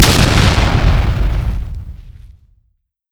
爆炸音效1.mp3